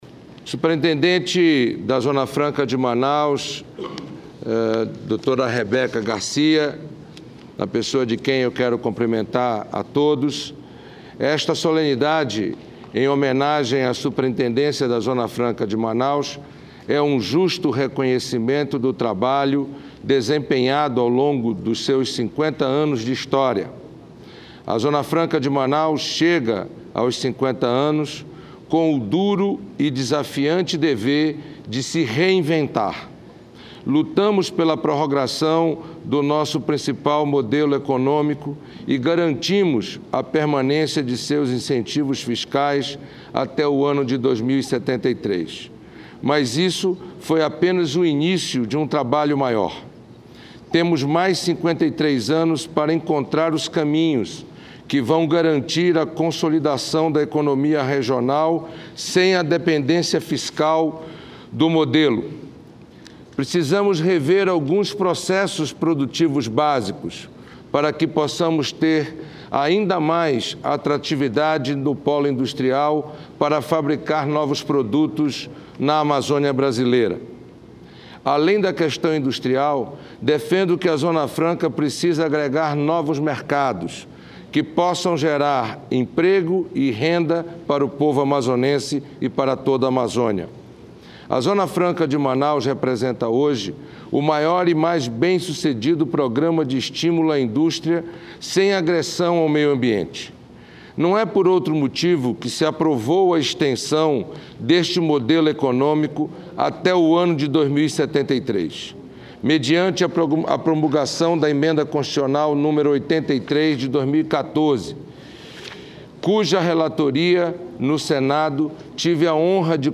Sessão Especial
Pronunciamento do senador Eduardo Braga